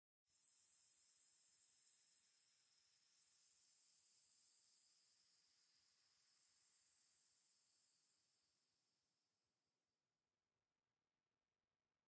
Minecraft Version Minecraft Version 1.21.5 Latest Release | Latest Snapshot 1.21.5 / assets / minecraft / sounds / block / firefly_bush / firefly_bush8.ogg Compare With Compare With Latest Release | Latest Snapshot
firefly_bush8.ogg